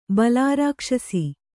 ♪ balārākṣasi